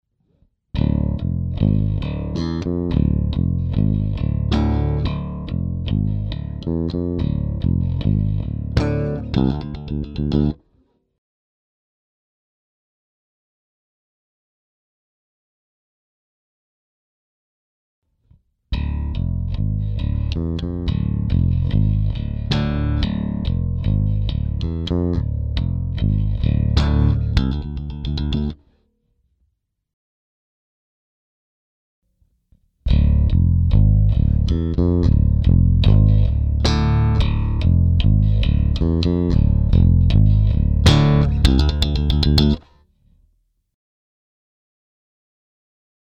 Der 3 Bänder klingt "kerniger", auch wenn ich die Mitten rausdrehe, der 2 Band schiebt mehr untenrum.
So hier ein kleiner (leihenhafter) Vergleich 2 Band (Bj 2000 Daddario EXL 170) v.s 3 Band Ray (Bj 2009 EB Hybrit Slinkies).
5. File 3 Band Ray mit Gnome mit unterschiedlichen EQ Einstellungen (Flat , B/T 75%/ M 50%, BT 100 %/M 25%)